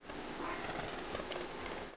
White-throated Sparrow diurnal flight calls
Fig.7. New York June 3, 1989 (WRE).
Perched bird.